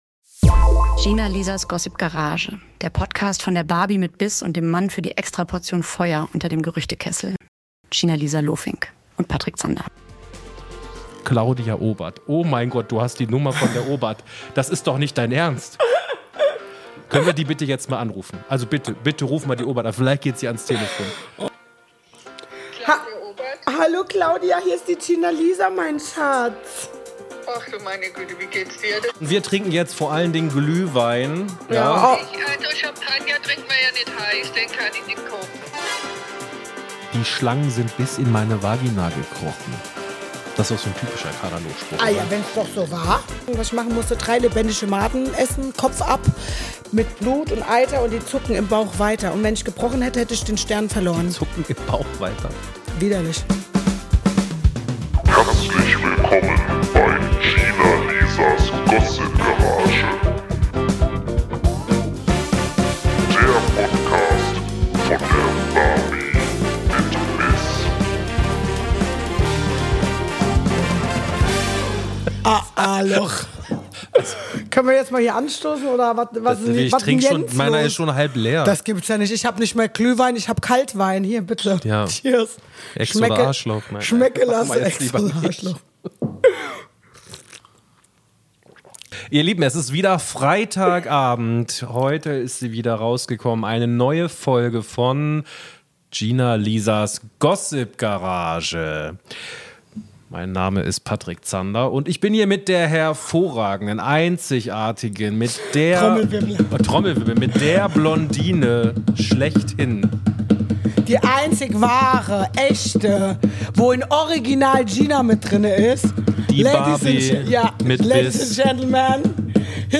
Claudia Obert, selbsternannte Schampus-Fashion-Ikone und Trash-TV-Sternchen am Telefon mit Gina-Lisa!